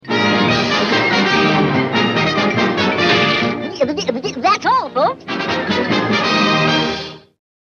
Category: Funny Ringtones